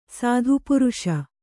♪ sādhu puruṣa